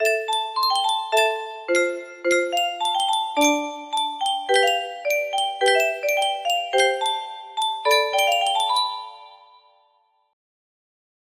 Contentment music box melody